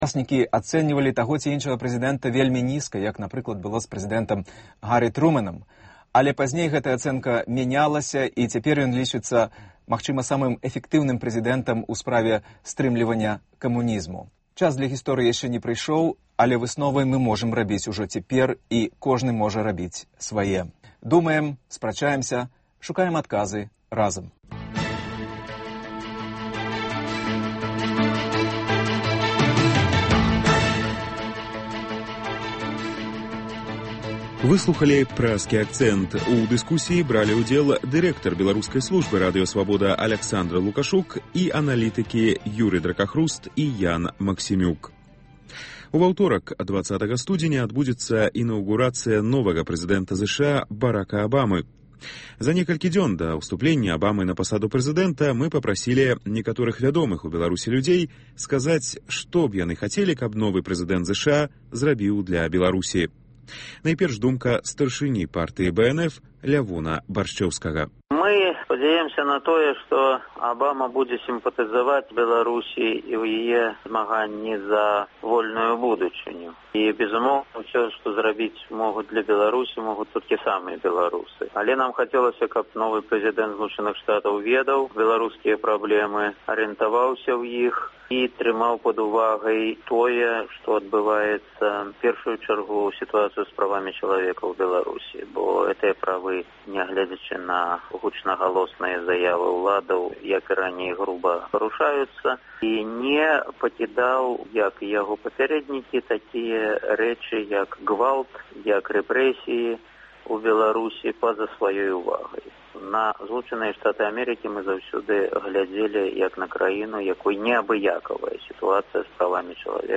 Агляд тэлефанаваньняў слухачоў